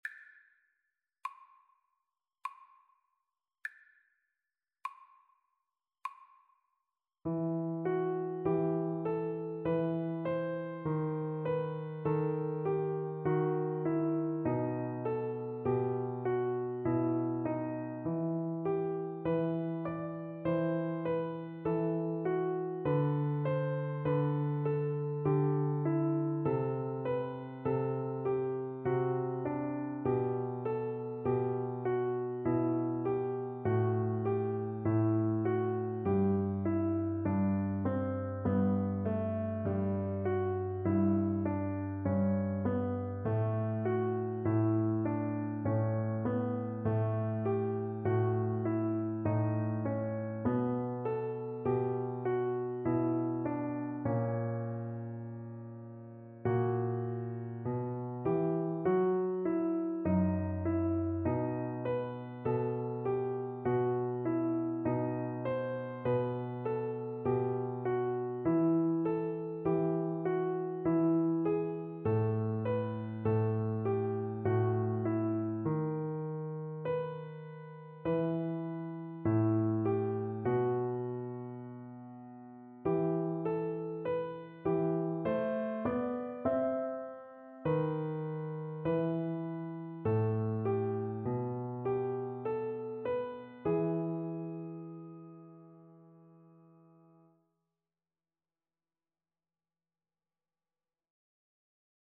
Cello
E minor (Sounding Pitch) (View more E minor Music for Cello )
Largo
3/4 (View more 3/4 Music)
A3-C5